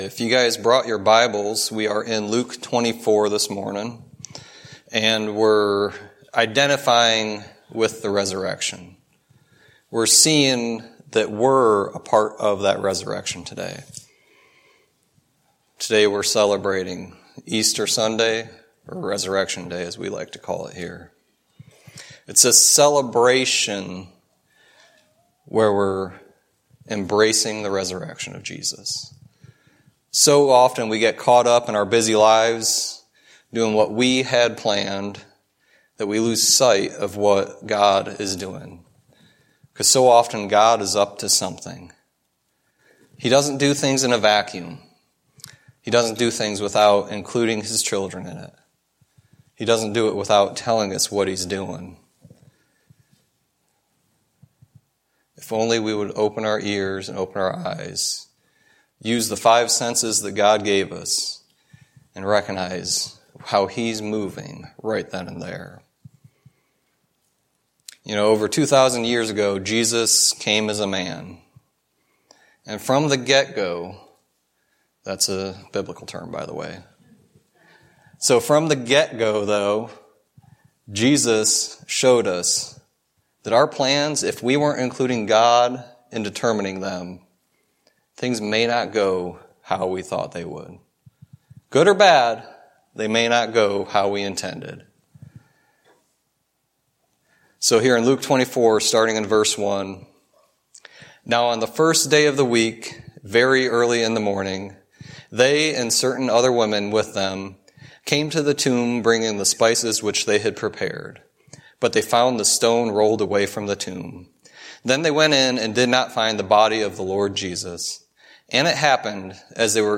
Luke 24:1-12 Service Type: Sunday Teaching There is great importance and power in the resurrection of Jesus Christ.